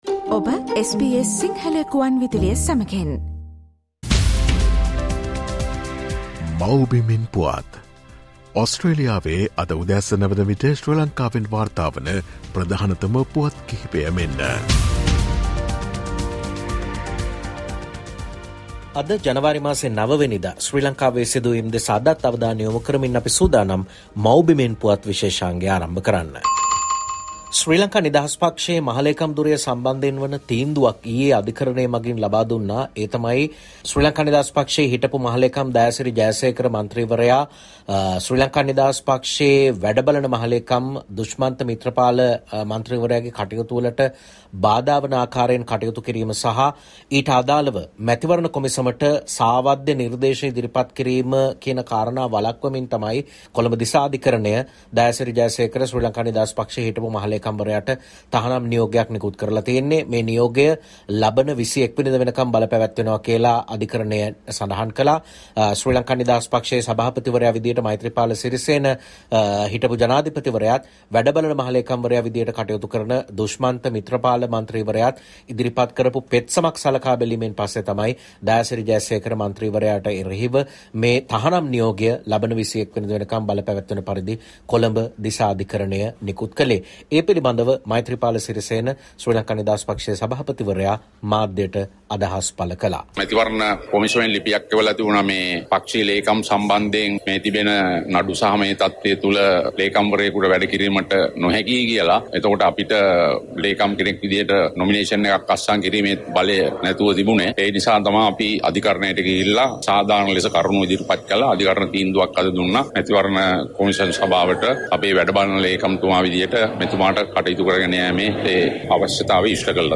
No one left SLPP, everyone just changed sides for their personal gain - Namal Rajapaksa :Homeland News 09 Jan